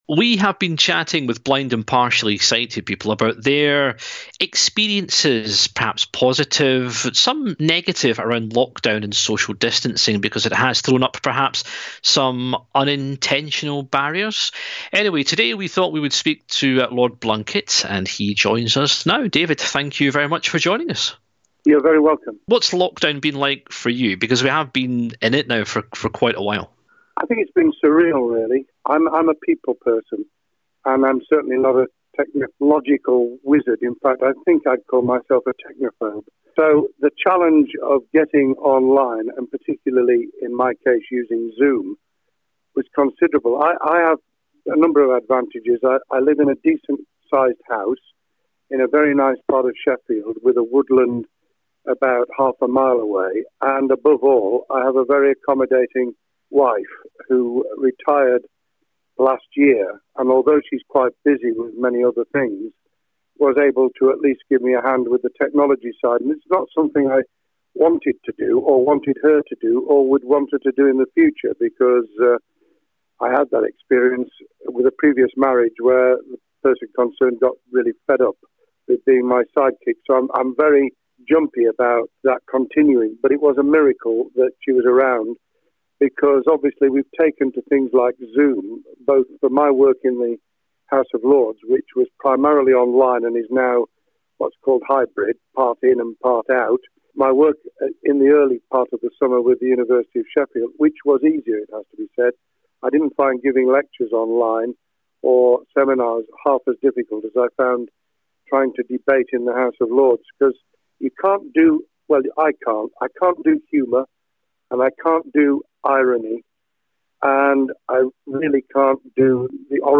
spoke to Lord Blunkett to find out how he, as a blind person, is learning new technology, encouraging visually impaired people not to stay indoors and what support should be offered by government to the 3rd sector.